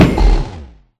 Sound / Minecraft / mob / enderdragon / hit4.ogg